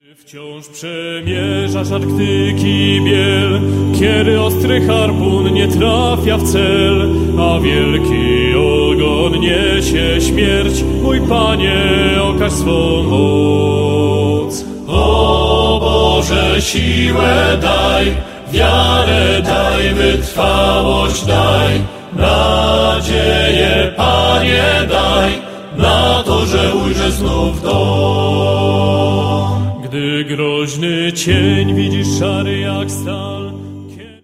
mel. trad.